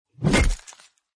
hammer1.mp3